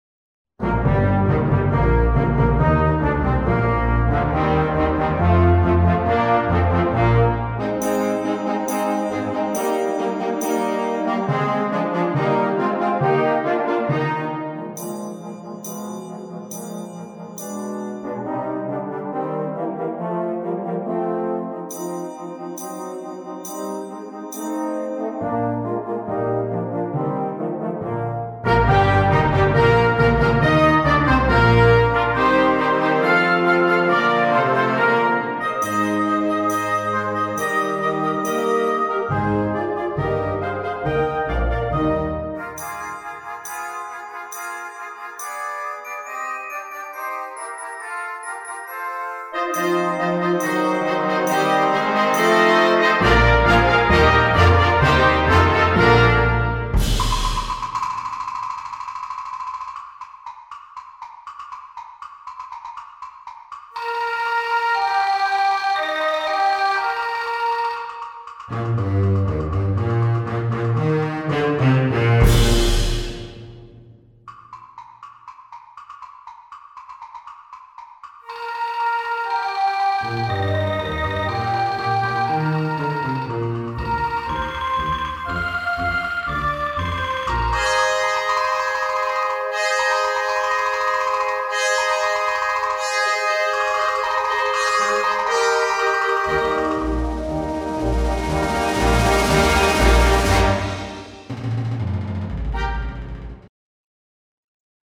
Gattung: Suite
Besetzung: Blasorchester
eine Fantasie für Blasinstrumente und Schlagzeug